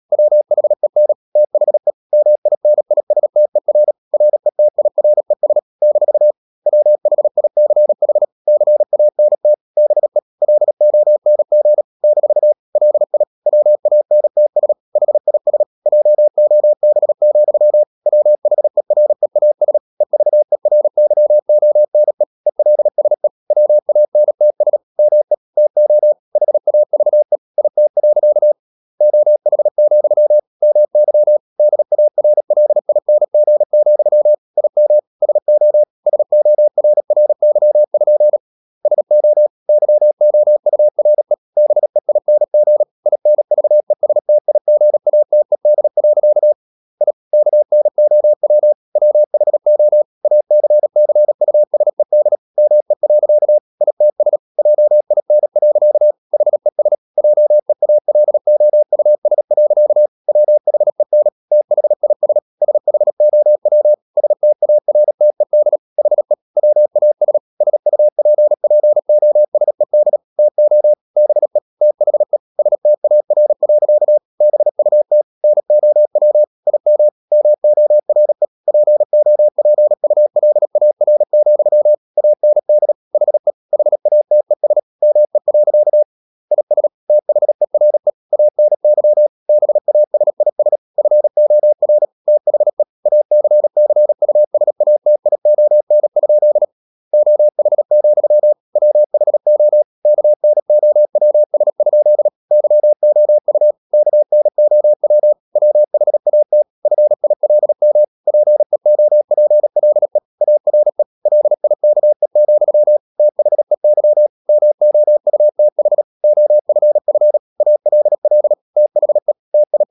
35 - 39 wpm | CW med Gnister
Romanen Never af Ken Follet på engelsk. LYD FIL: Never_0037wpm.mp3 Hastighed: 35 - 39 wpm Sprog: Engelsk Rate: Select rating Give Never 37wpm 1/5 Give Never 37wpm 2/5 Give Never 37wpm 3/5 Give Never 37wpm 4/5 Give Never 37wpm 5/5 No votes yet
Never_0037wpm.mp3